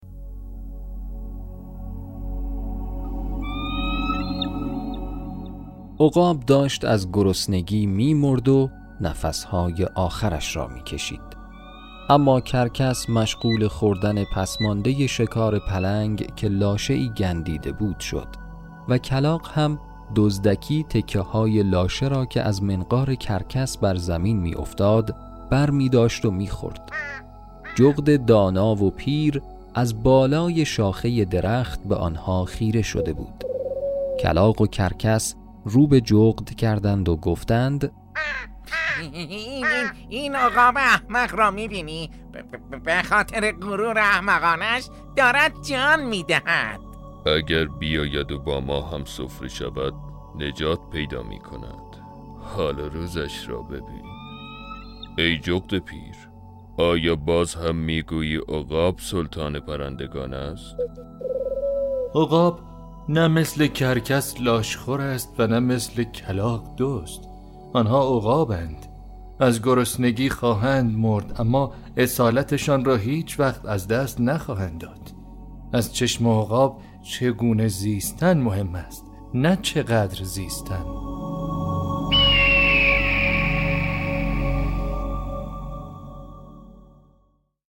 داستان صوتی کوتاه - سلطان پرندگان - میقات مدیا
صداپیشگان :